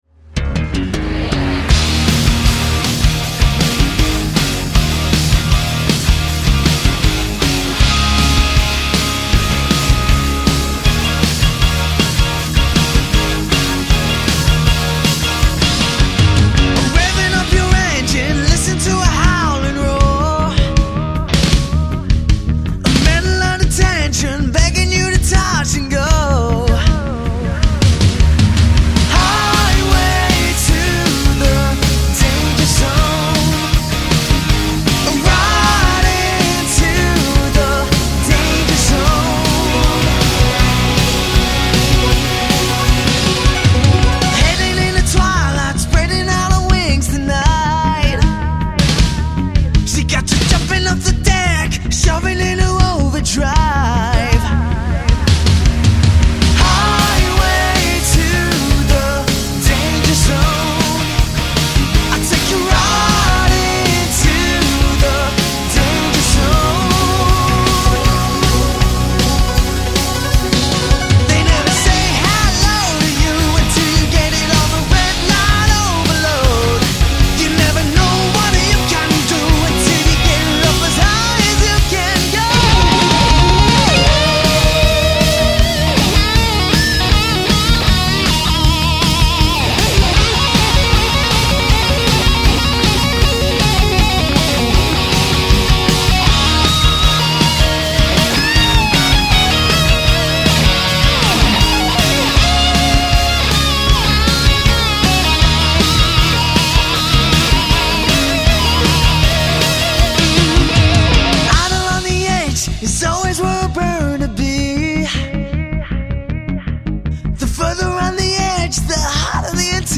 80s Tribute Band | 80s Cover Band
• 4-piece
Vocals, Guitar, Bass, Drums